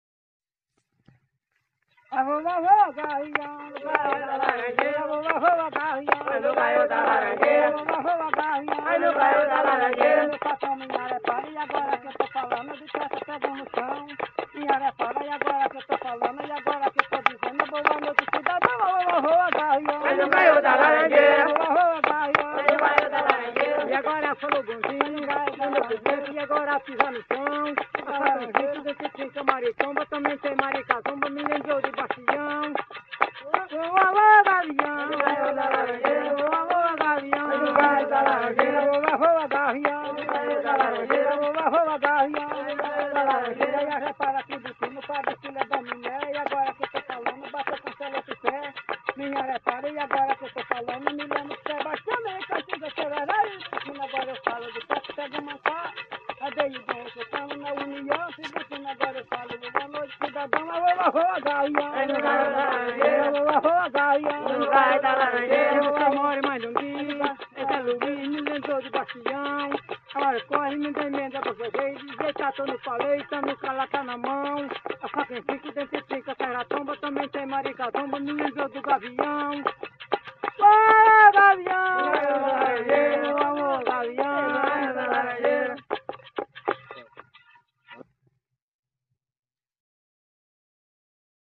Coco embolada -“”Voa, voa gavião”” - Acervos - Centro Cultural São Paulo